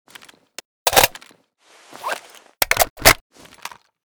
l85_reload.ogg.bak